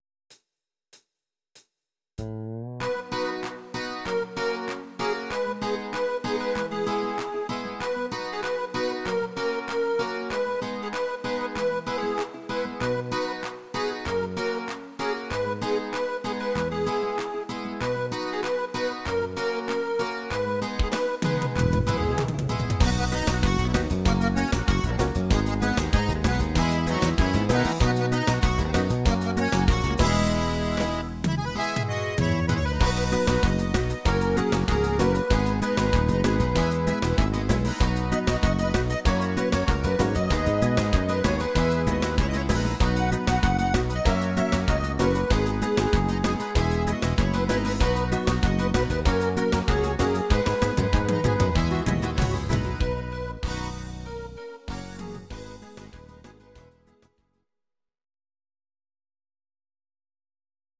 Audio Recordings based on Midi-files
Pop, Ital/French/Span, 2010s